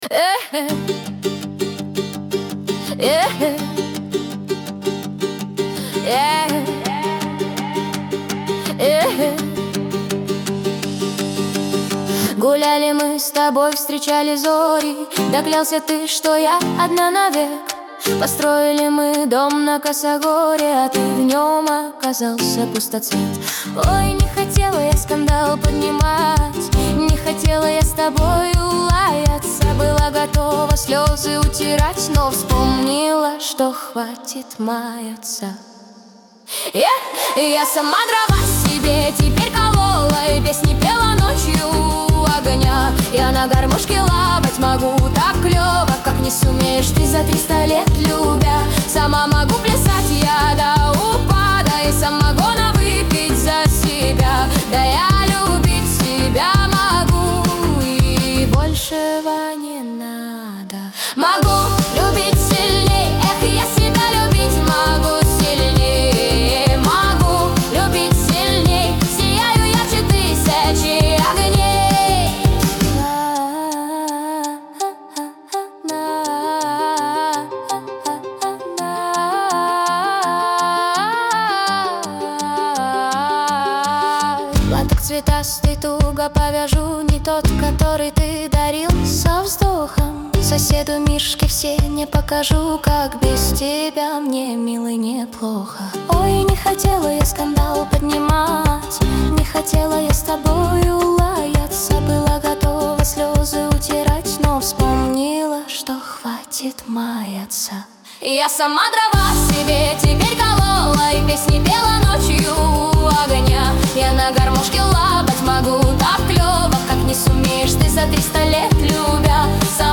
Главная ➣ Жанры ➣ Поп музыка. 2026.
грусть , диско
Лирика